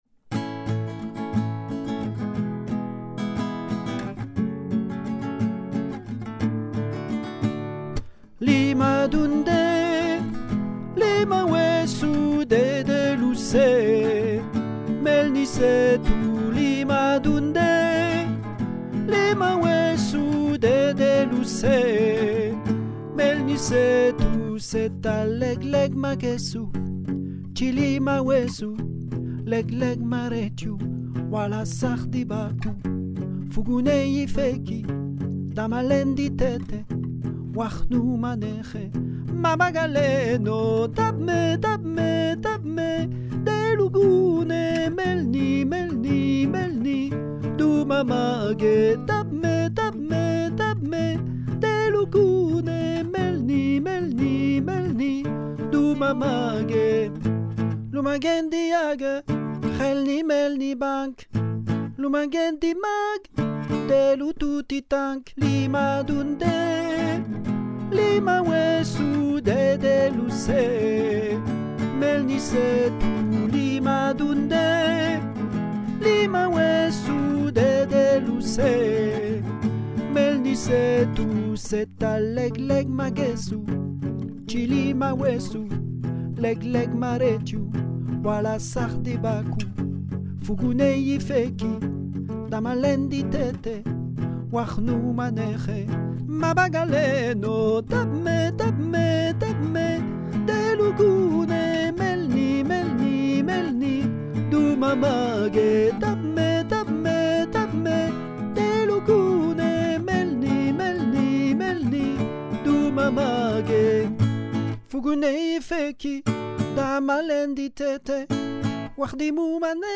voix 2 :